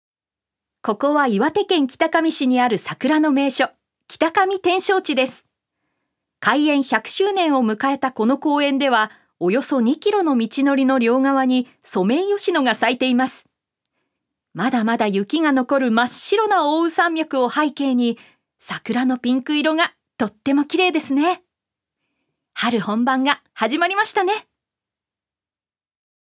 女性タレント
ナレーション３